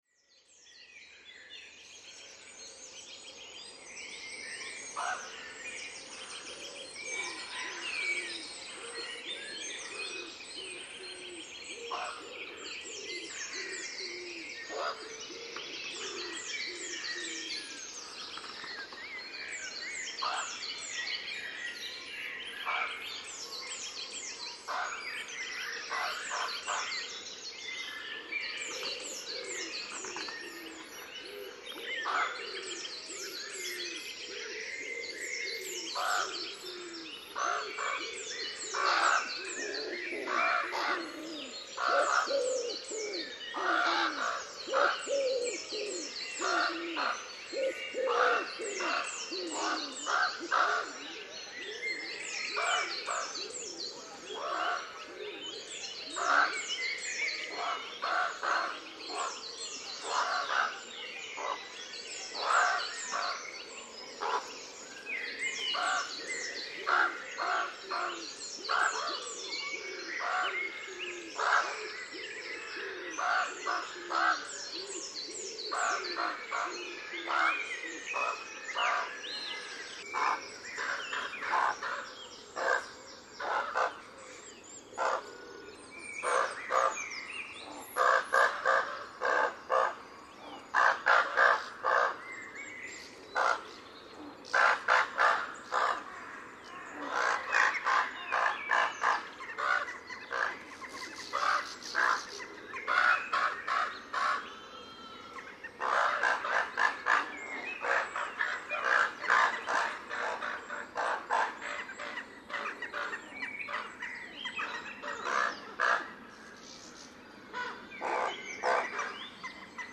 Ravens
I suspect the eggs hatched on Thursday last week as they are much noisier all of a sudden.
This is a medley of sounds (6 mins 45secs)  recorded on Saturday morning (23rd)
raven.mp3